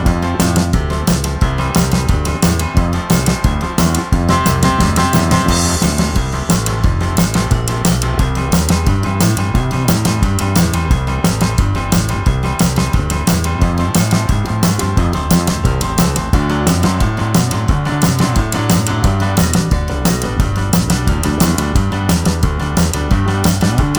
Minus Piano Rock 'n' Roll 3:20 Buy £1.50